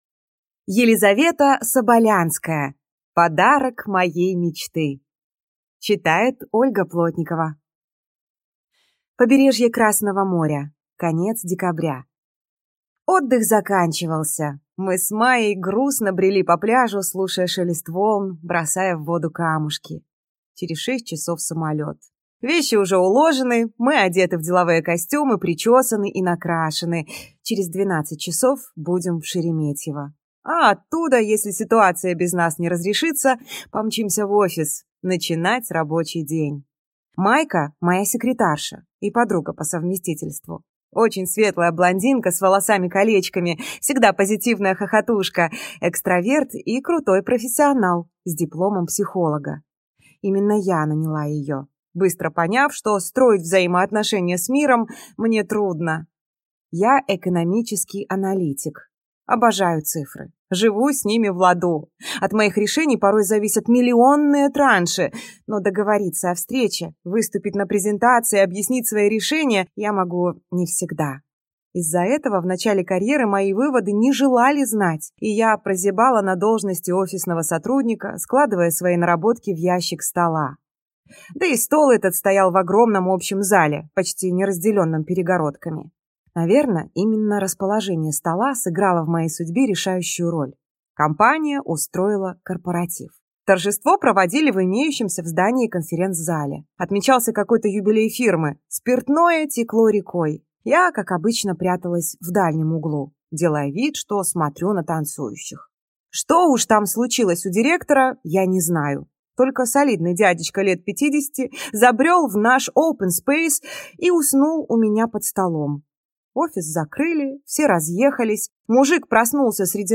Аудиокнига Подарок моей мечты | Библиотека аудиокниг